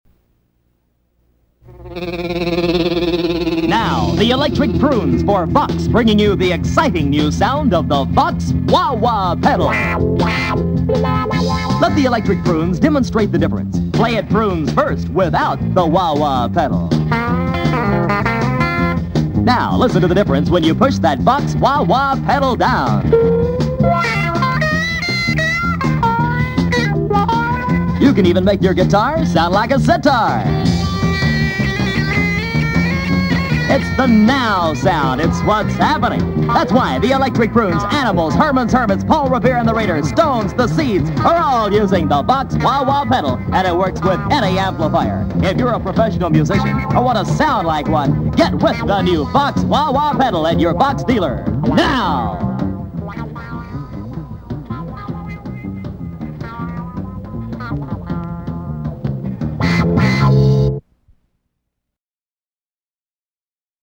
Electric Prunes Vox Wah Wah Ad
07-electric-prunes-vox-wah-wah-spo.mp3